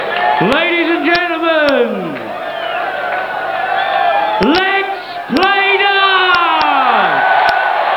Ein kurzes aber bedeutendes Statement von Martin Fitzmaurice, dem ber�hmtesten Caller der Welt! A short but important statement from Martin Fitzmaurice, the worlds most famous caller!